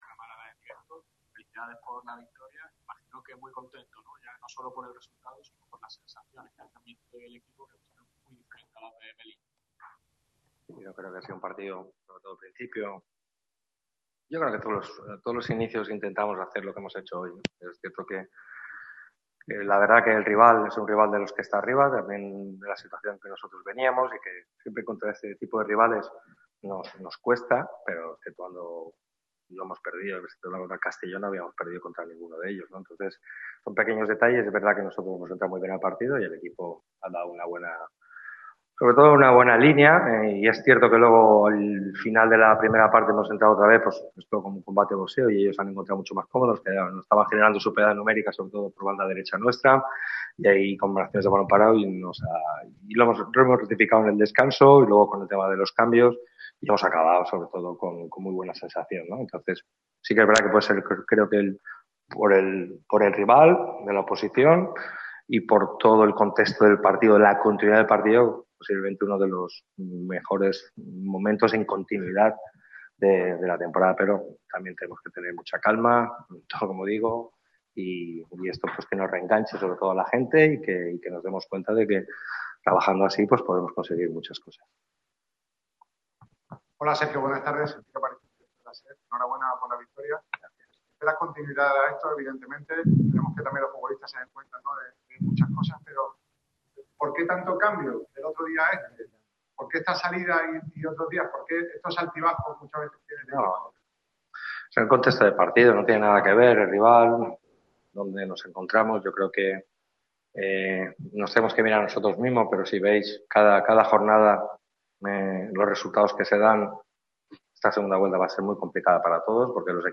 rueda de prensa postpartido tras la victoria del Málaga CF por 2-0 frente al Recreativo de Huelva.